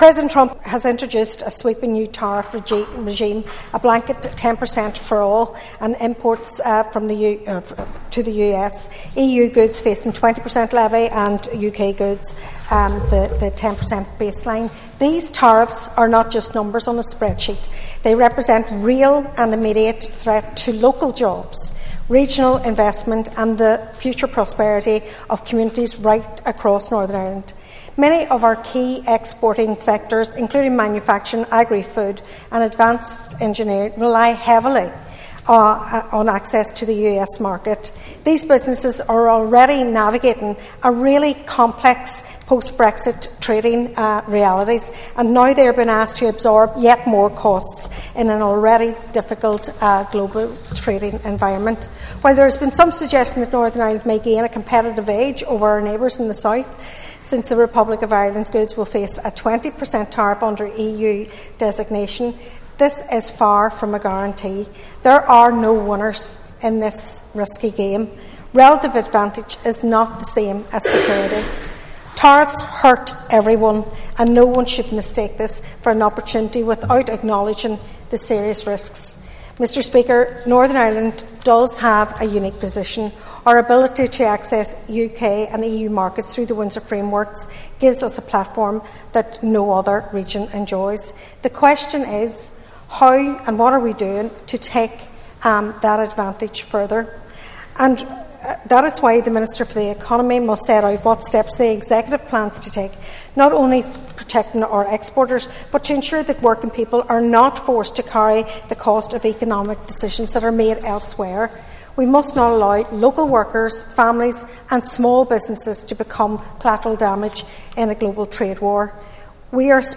Sinead McLaughlin told the Assembly it’s vital that there is consultation and cooperation on a cross border basis, and also internationally………..